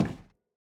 added stepping sounds
Flats_Metal_Grate_004.wav